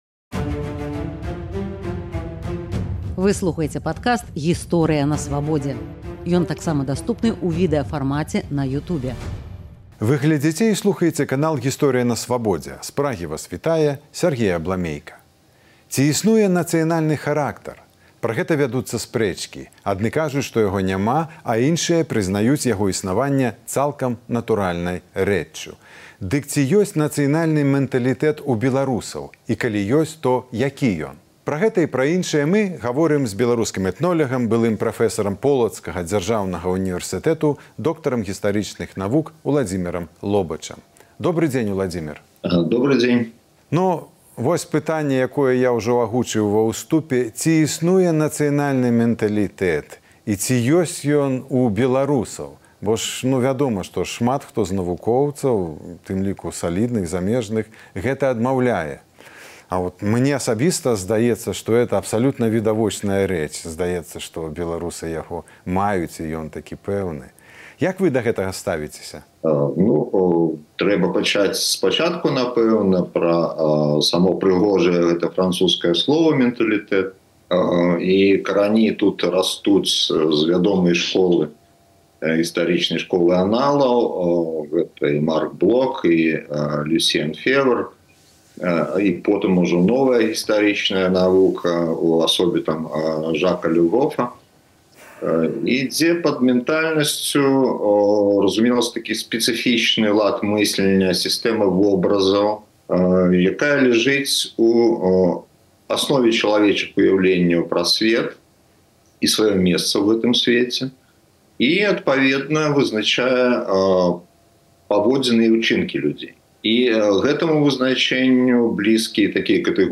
Мы гаворым зь беларускім этнолягам